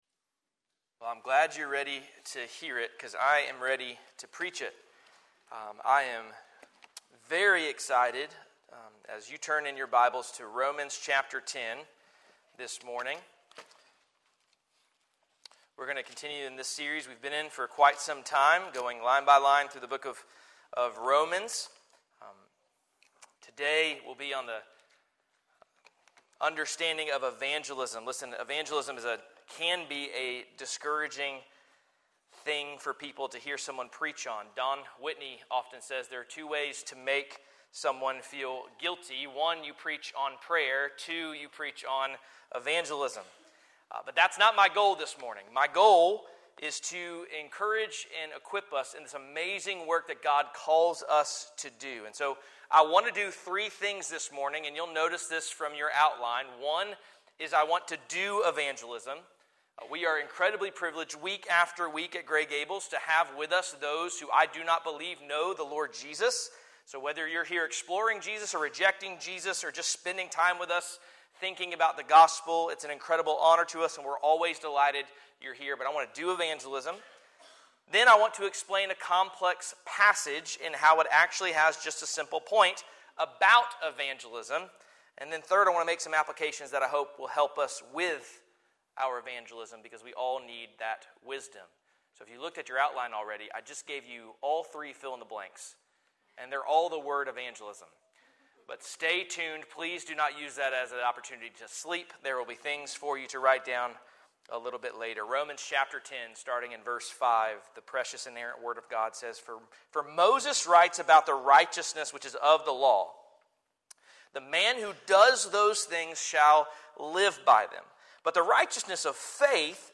Sermons | First Baptist Church of Gray Gables